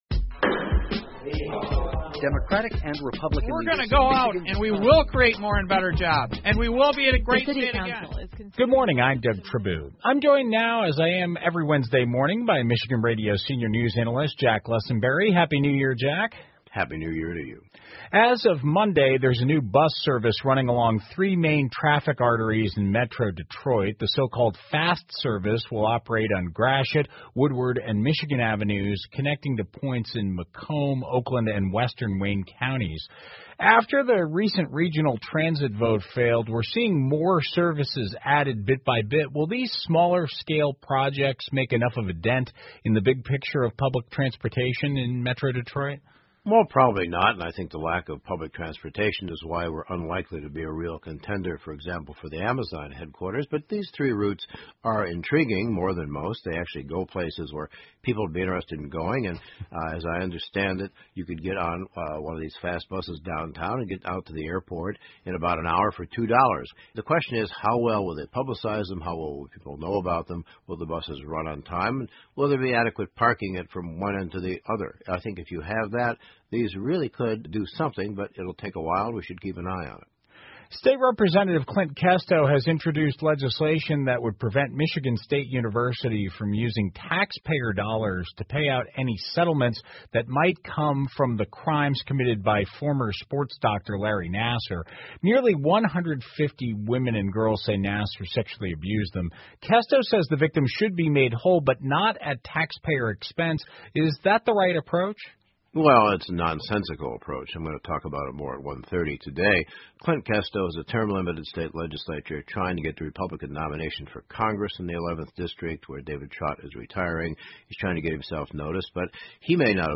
密歇根新闻广播 兰辛新市长 听力文件下载—在线英语听力室